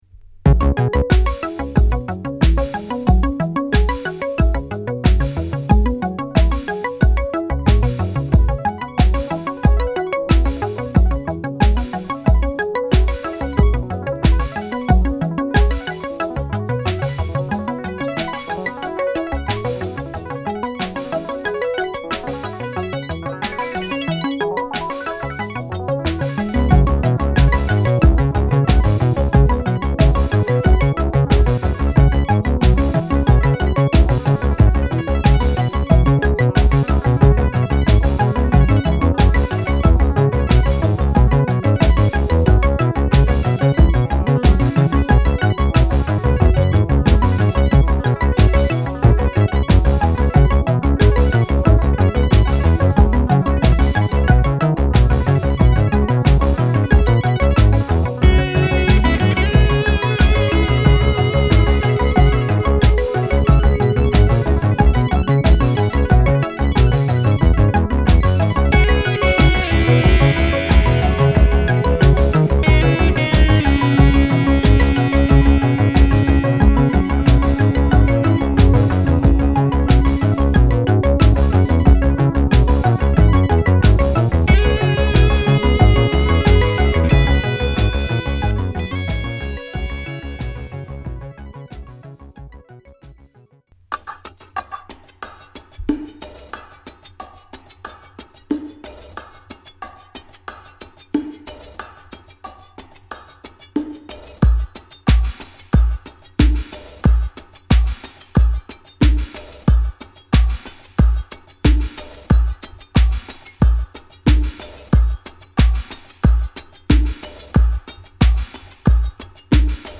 宇宙彼方からのコズミックなヴァイヴスとオリエンタルな幻影
バレアリックな世界観